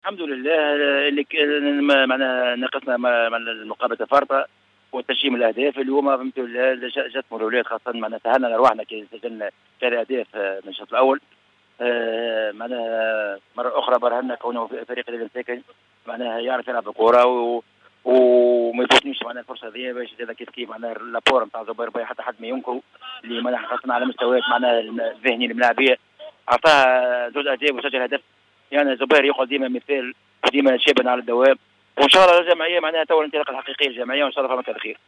مباراتنا بالأمس أمام الرديف هي بداية الانطلاقة الحقيقية للهلال(تصريح)